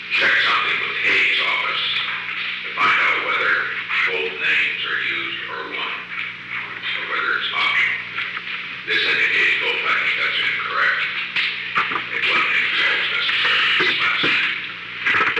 Recording Device: Oval Office
The Oval Office taping system captured this recording, which is known as Conversation 690-004 of the White House Tapes.